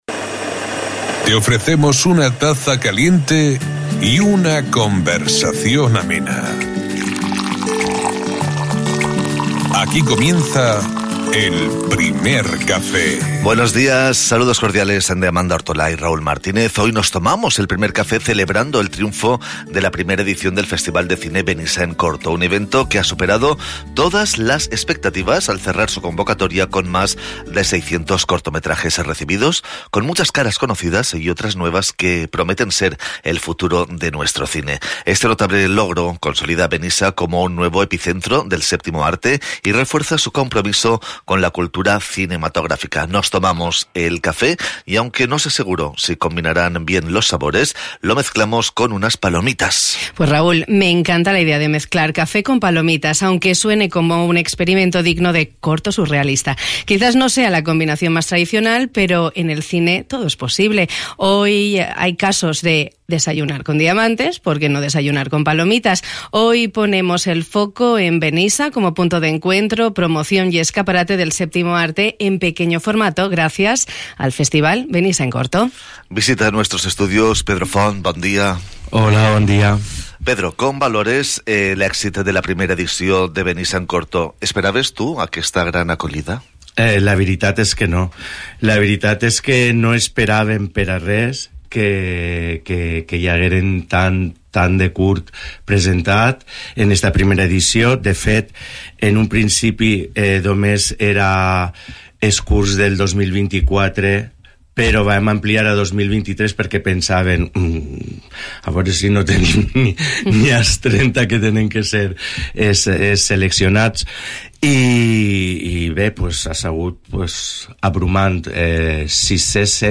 Este miércoles, 7 de mayo, hemos tenido un Primer Café de cine.